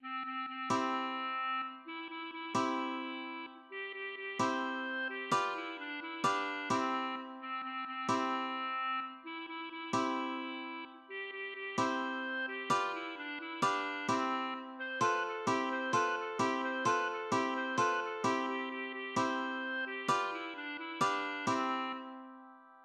(gesungen anlässlich des Bardenwettbewerbs zur Krönung Großfürsts Alderan von Gareth zu Schloss Auenwacht ) Texte der Hauptreihe: K1.